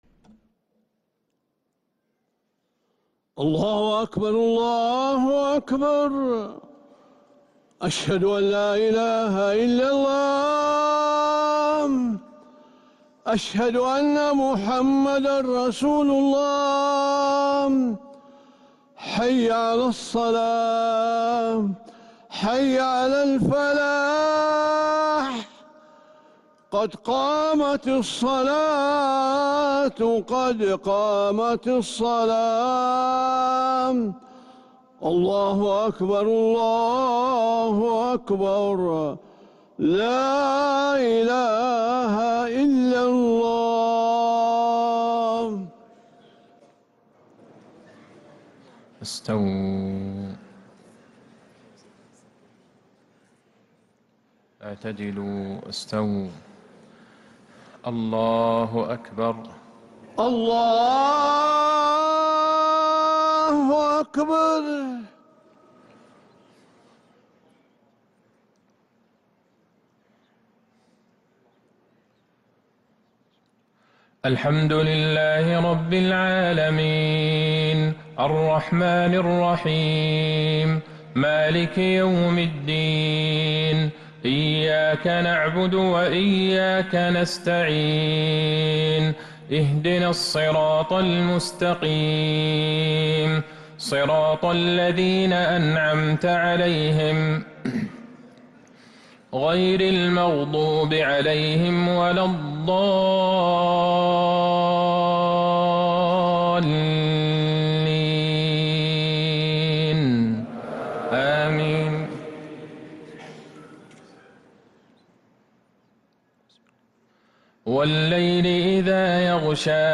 Madeenah Isha - 12th April 2026